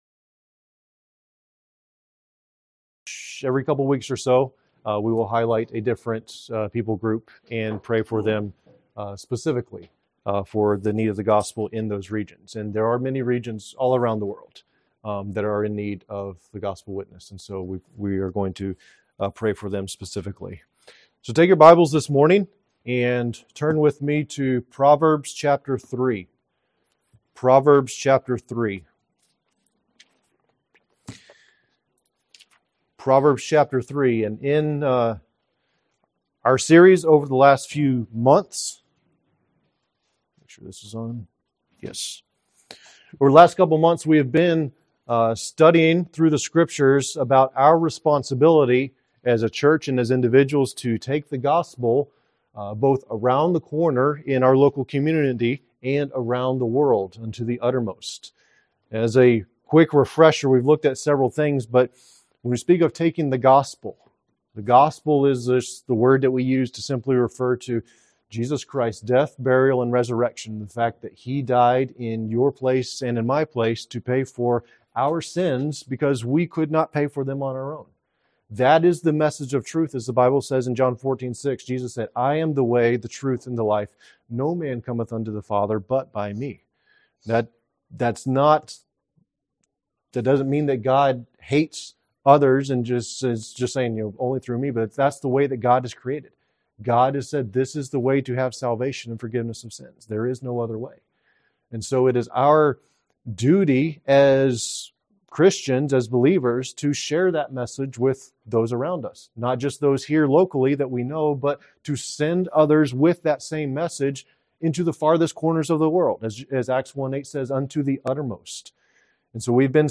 This message continues to lay the foundation for giving by examining the last two principles of biblical giving. NOTE: There was a technical glitch part-way through the message, so please excuse the abrupt jump. There are only a few moments missing.